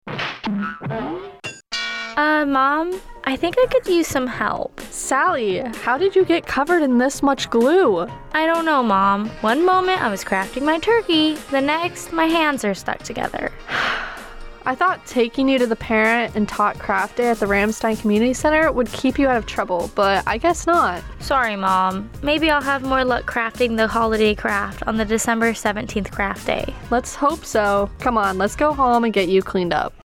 Radio Spot- Parent and Tot Holiday Craft